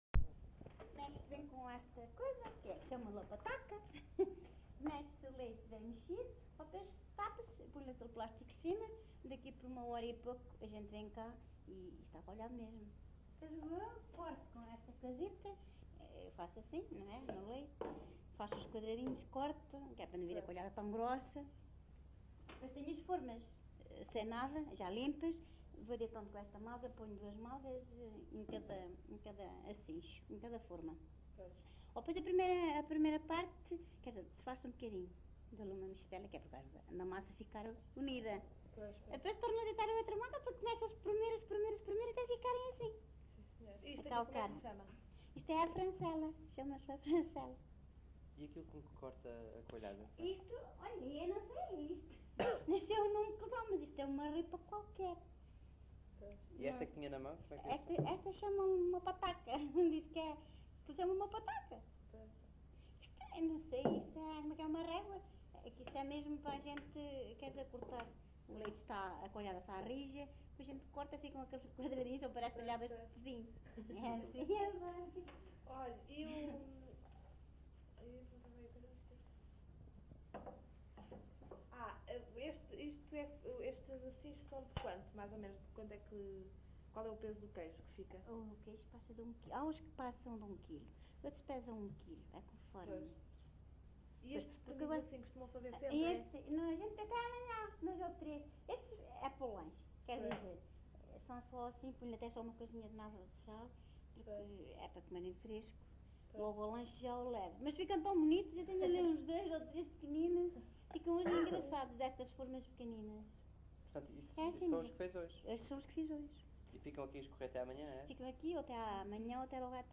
LocalidadeMonsanto (Idanha-a-Nova, Castelo Branco)